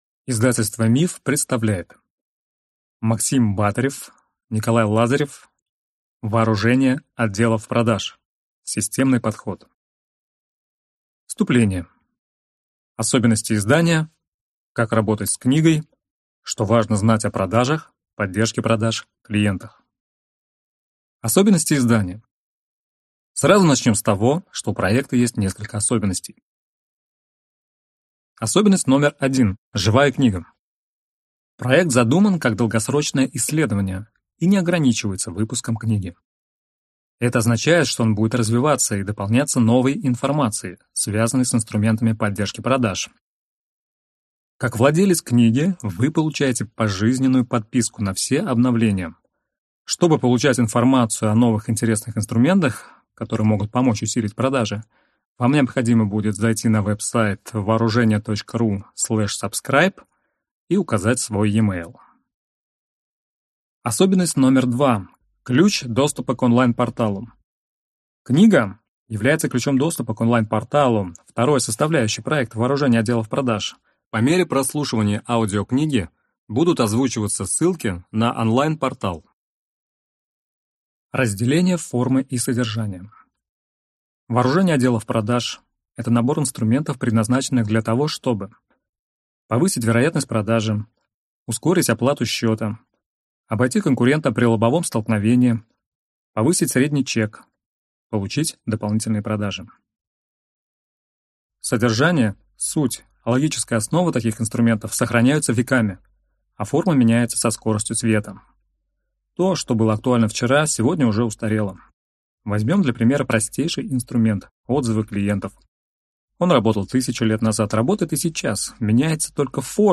Аудиокнига Вооружение отделов продаж. Системный подход | Библиотека аудиокниг